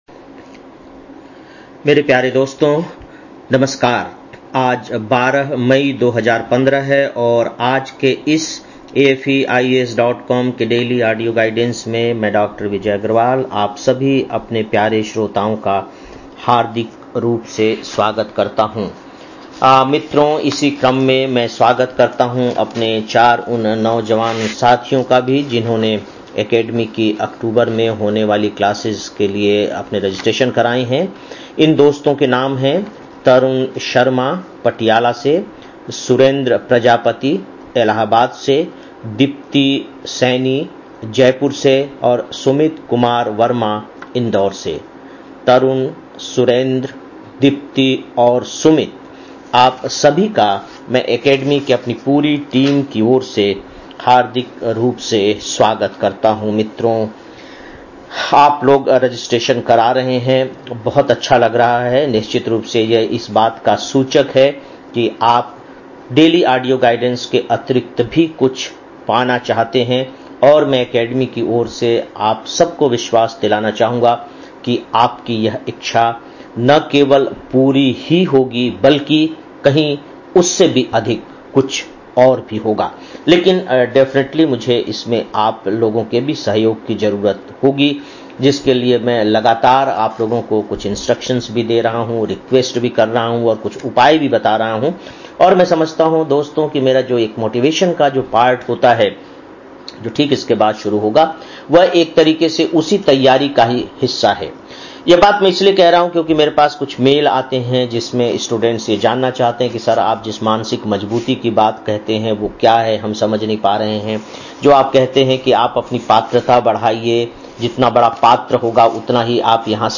12-05-15 (Daily Audio Lecture) - AFEIAS